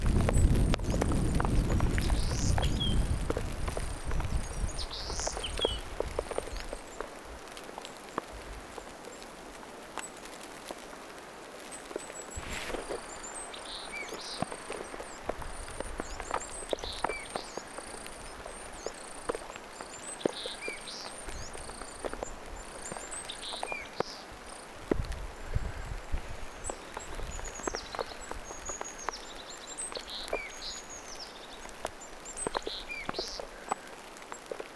kuusitiainen sirittää,
hömötiainen laulaa
kuusitiainen_sirittaa_homotiainen_laulaa.mp3